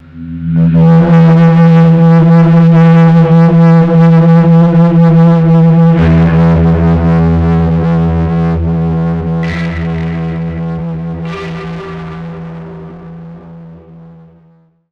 GUITARFX 9-L.wav